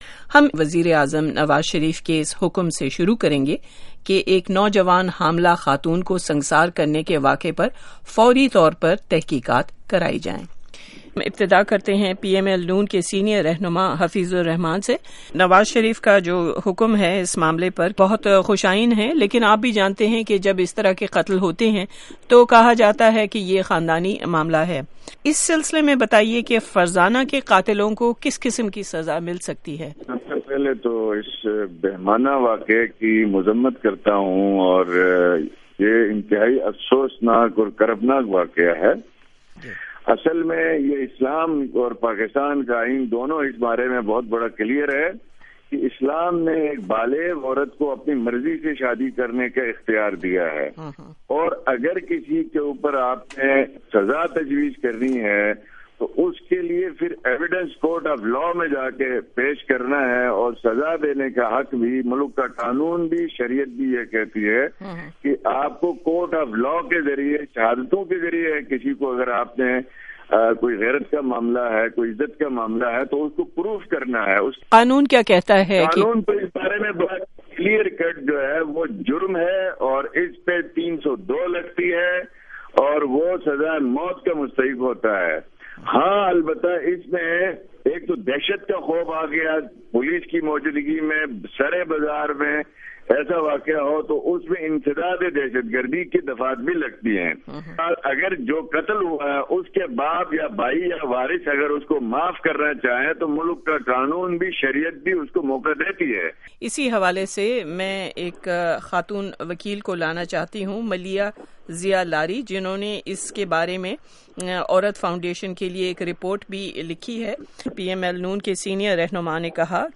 ITN interview with experts, honor killing